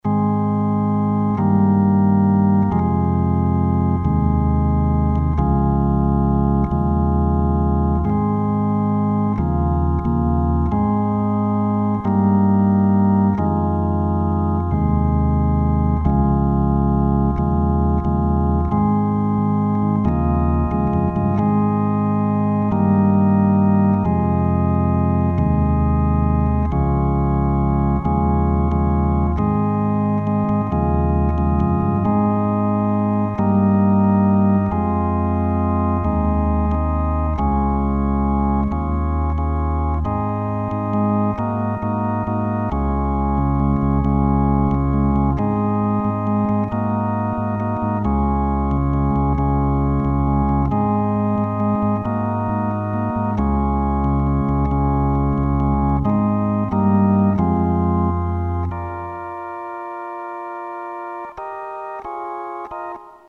Drawbar Sound Module (1993)
Organ module expander with optional XMC-1 drawbars control.